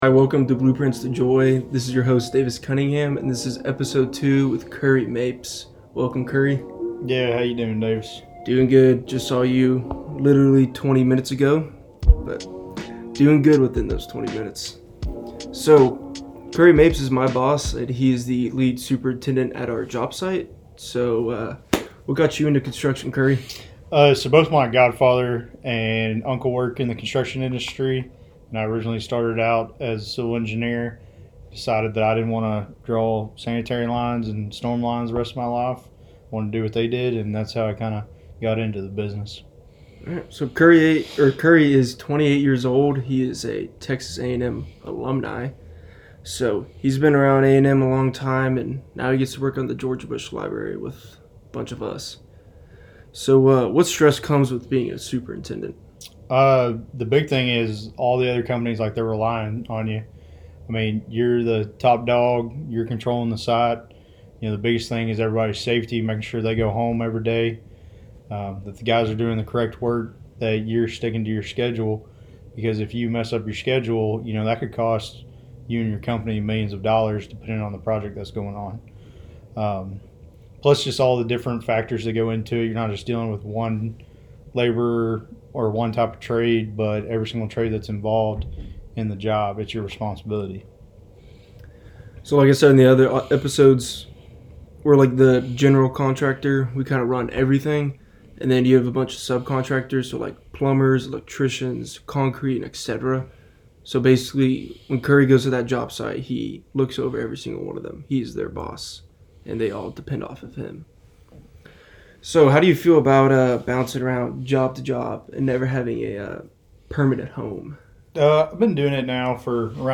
Podcasts for Aggie students by Aggie students